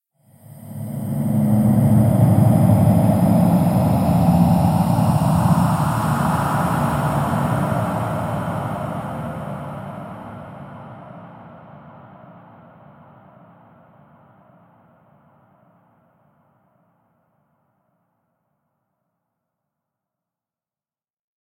Звук пролетающих призраков
zvuk_proletayushih_prizrakov_1x9.mp3